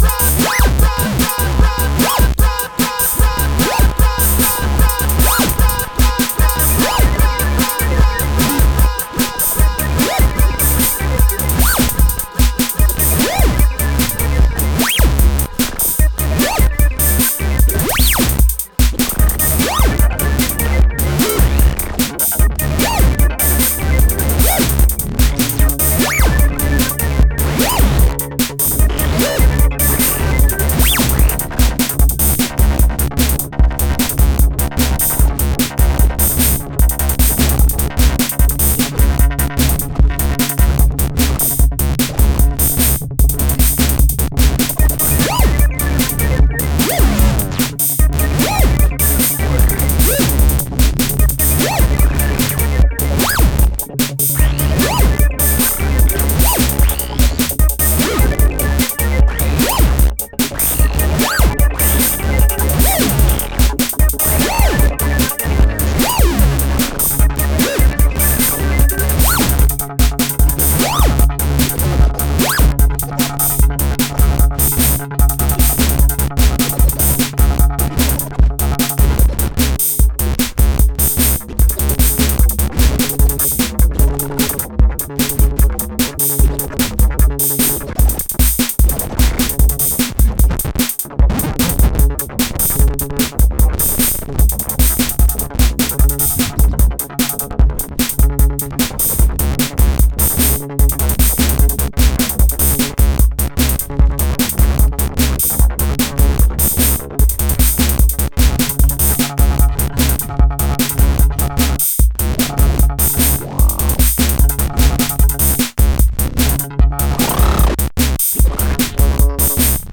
commentaires {0} Description composition musique electronique Accueil Mon Profil Archives Amis Derniers Articles